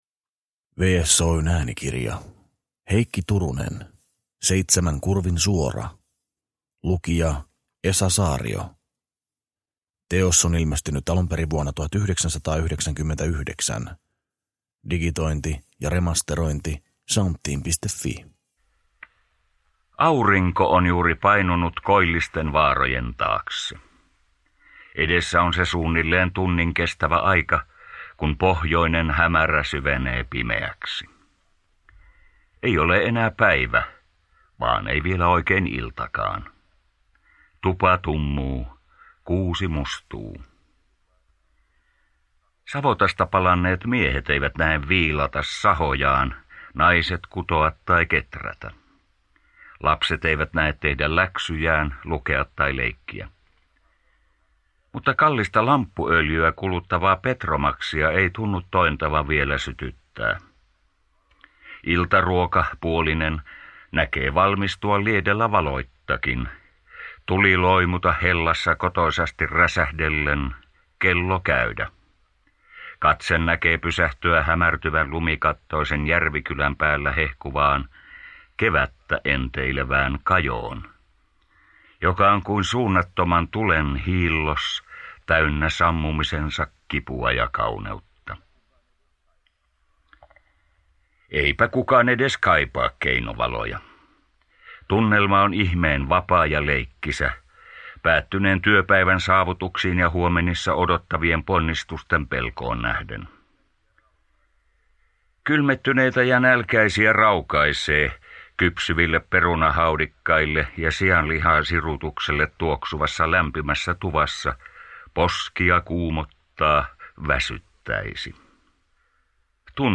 Seitsemän kurvin suora – Ljudbok